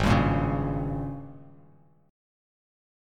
AbM7sus2sus4 chord